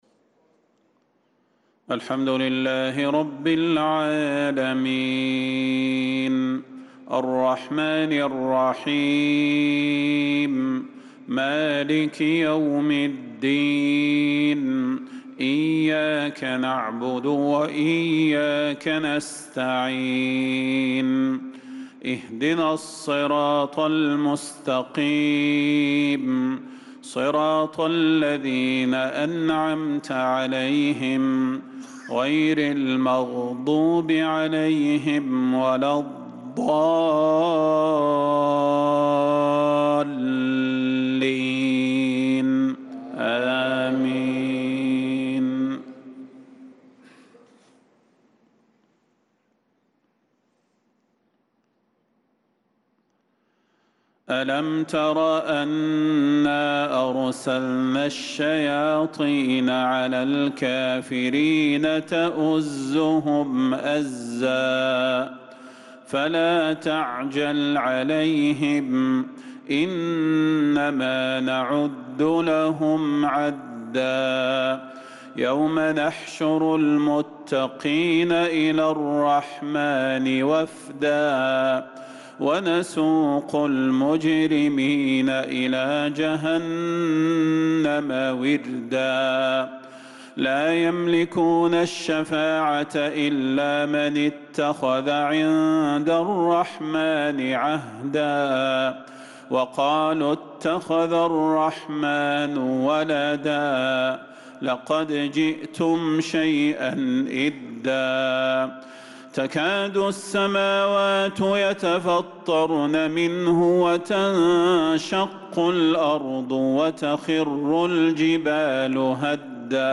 صلاة المغرب للقارئ صلاح البدير 21 ذو القعدة 1445 هـ
تِلَاوَات الْحَرَمَيْن .